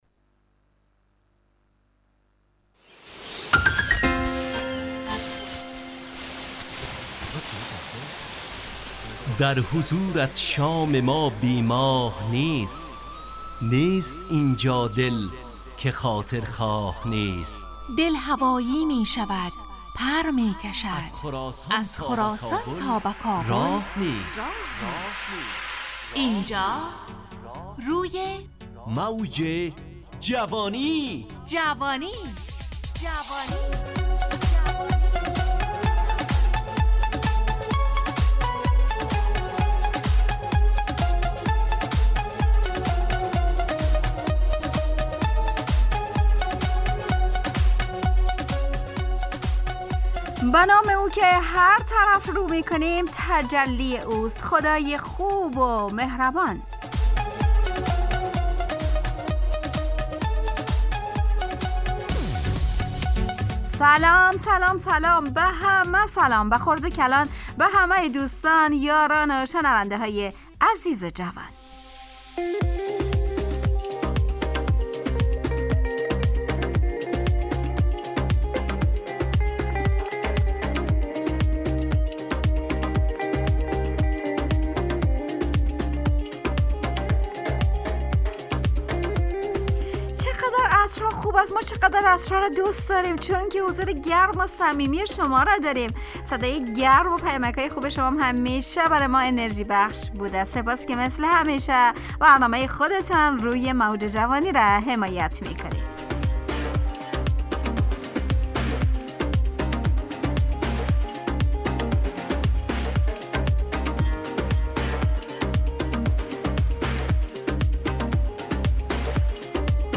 همراه با ترانه و موسیقی مدت برنامه 70 دقیقه . بحث محوری این هفته (غیرت)
برنامه ای عصرانه و شاد